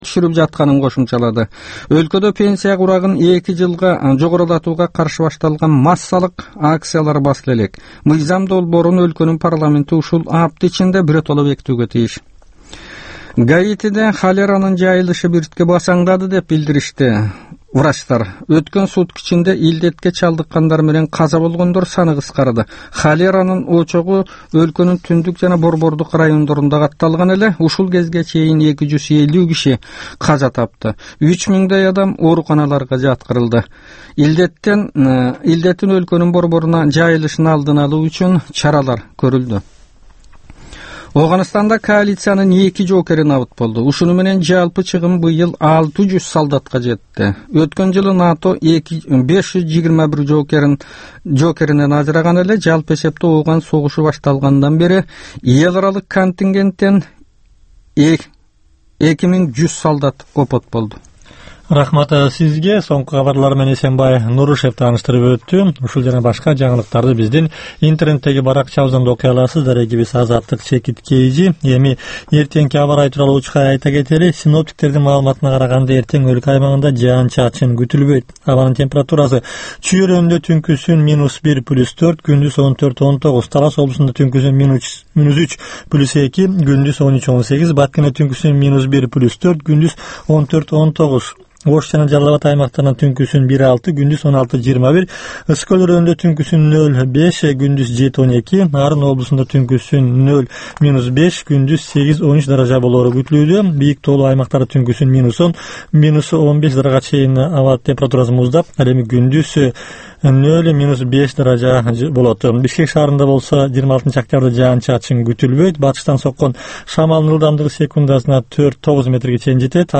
"Азаттык үналгысынын" бул кечки алгачкы жарым сааттык берүүсү жергиликтүү жана эл аралык кабарлар, репортаж, маек, аналитикалык баян, сереп, угармандардын ой-пикирлери, окурмандардын э-кат аркылуу келген пикирлеринин жалпыламасы жана башка берүүлөрдөн турат. Бул үналгы берүү ар күнү Бишкек убакыты боюнча саат 18:00ден 18:30га чейин обого түз чыгат.